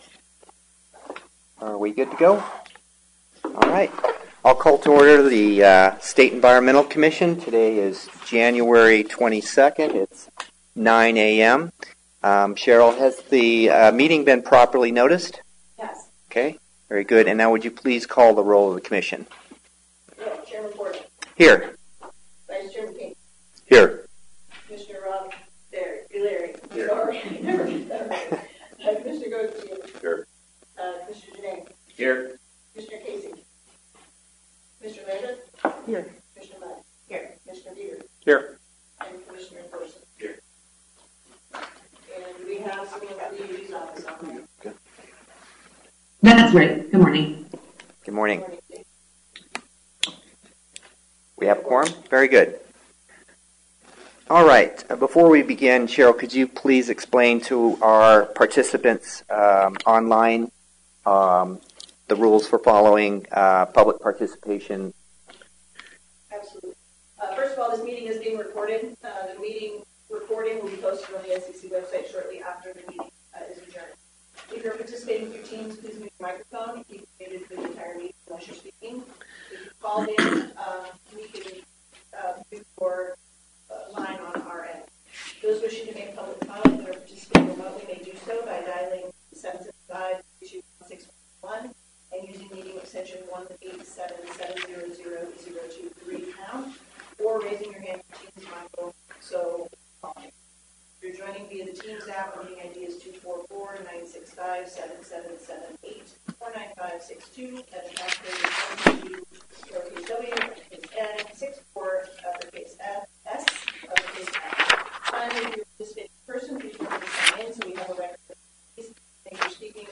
Bryan Building, 1st Floor (Bonnie B. Bryan Room) 901 South Stewart Street Carson City, NV
NDEP Las Vegas Office (Red Rock Conf Room) 375 East Warm Springs Road Las Vegas, NV